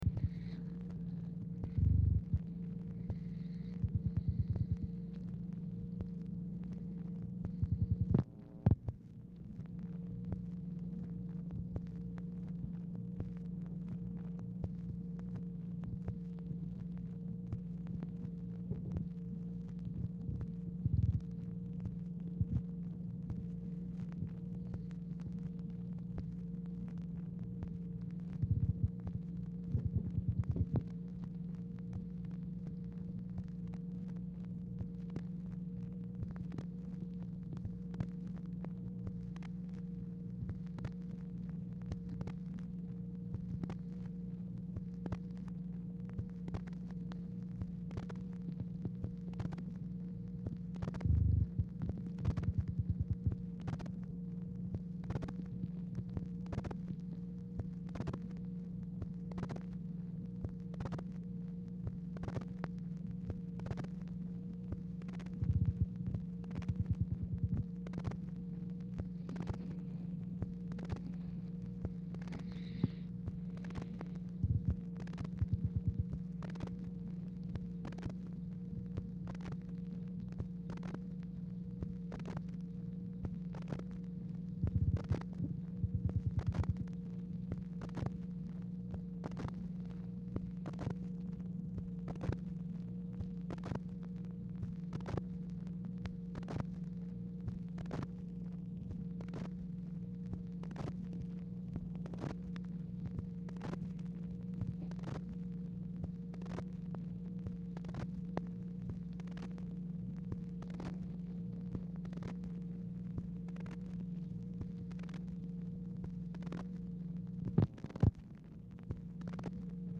Telephone conversation # 4324, sound recording, OFFICE NOISE, 7/23/1964, time unknown | Discover LBJ
Format Dictation belt